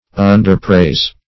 Underpraise \Un`der*praise"\